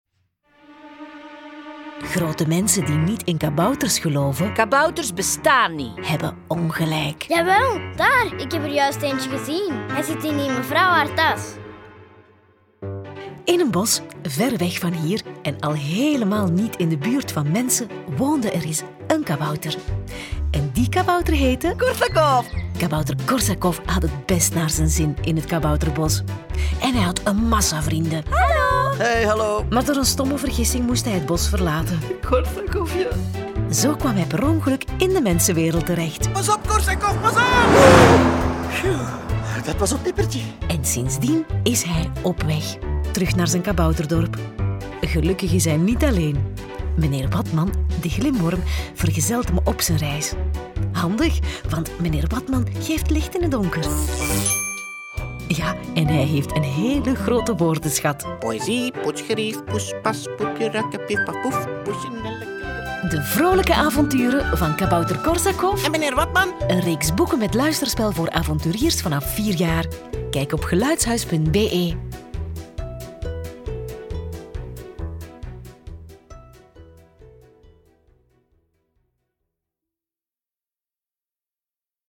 Kabouter Korsakov is een reeks luisterverhalen voor avonturiers vanaf 4 jaar.